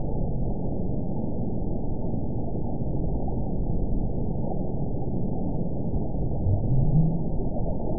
event 921165 date 05/01/24 time 17:47:44 GMT (1 year, 7 months ago) score 9.32 location TSS-AB04 detected by nrw target species NRW annotations +NRW Spectrogram: Frequency (kHz) vs. Time (s) audio not available .wav